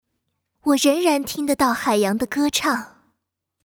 女声
守望先锋全女英雄模仿-4安娜